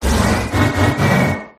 regidrago_ambient.ogg